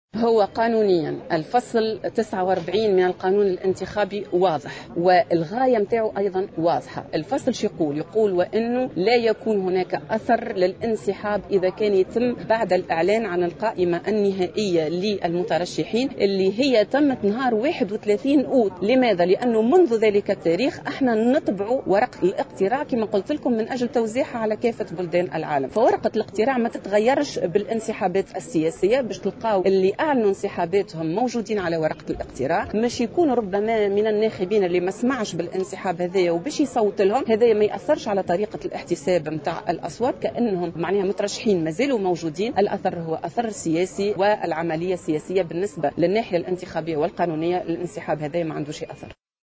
في تصريح للجوهرة "أف أم"